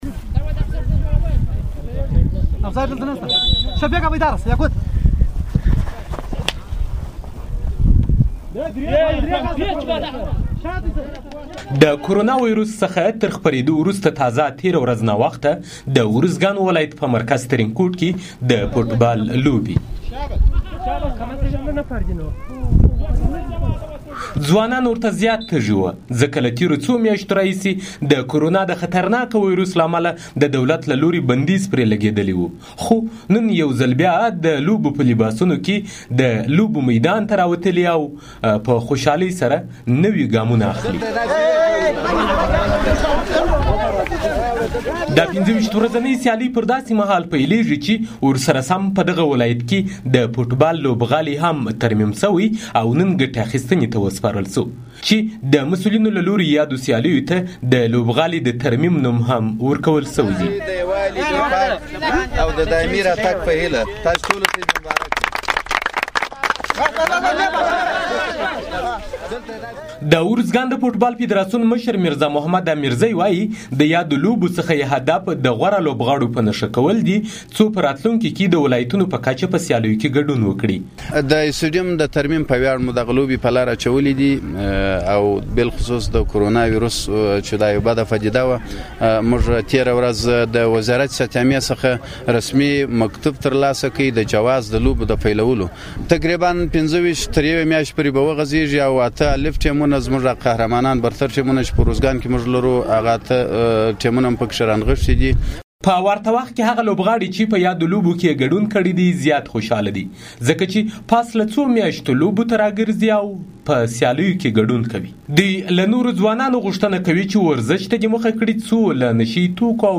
ارزګان کې د فوټبال سیالیو په اړه راپور